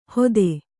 ♪ hode